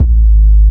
07 kick hit.wav